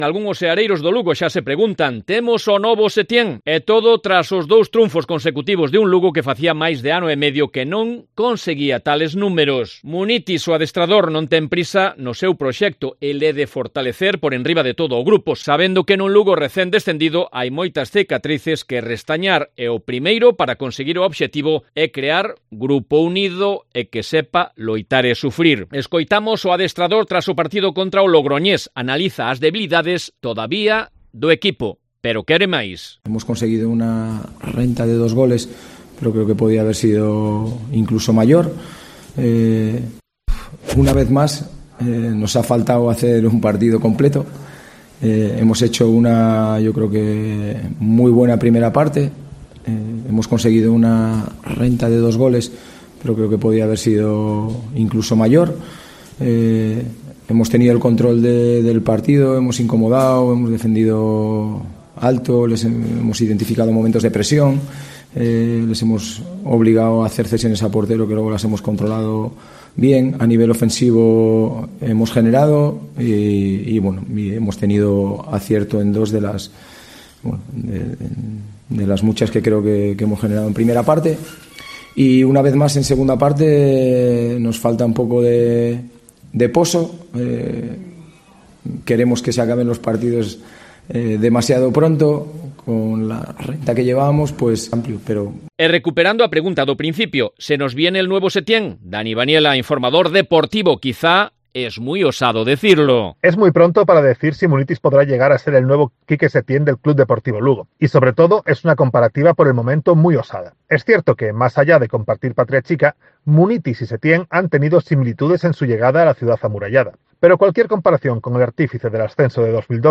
colaborador Cope